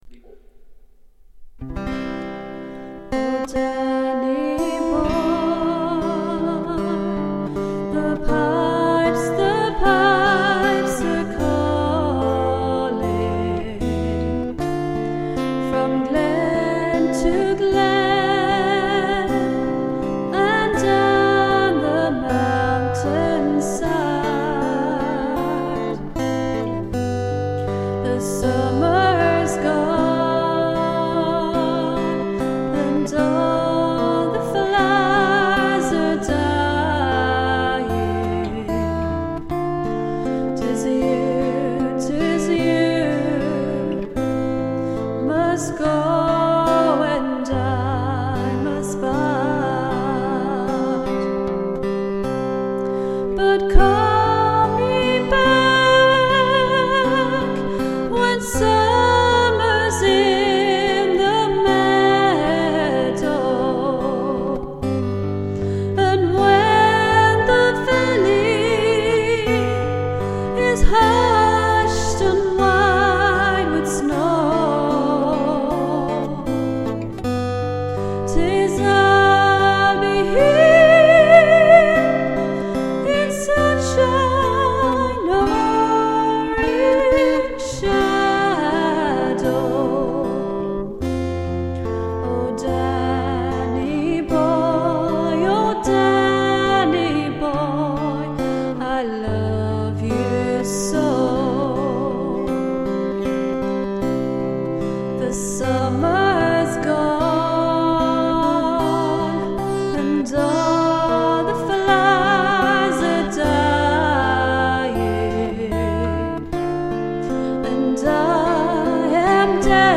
My very first demo using sonar and rode mic. And as Danny boy has just turned one hundred years here we go :) will always love it. yes mind the clip at the end lol never said i was a producer lol anyway back to my cold round two of getting rid of it lol